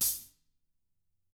Index of /90_sSampleCDs/ILIO - Double Platinum Drums 2/Partition D/THIN A HATD